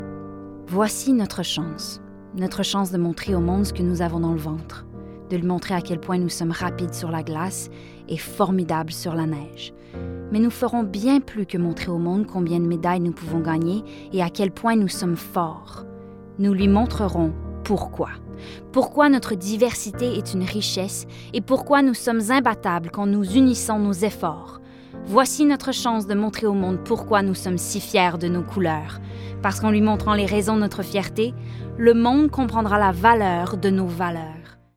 French-Canadian, Female, Home Studio, 20s-30s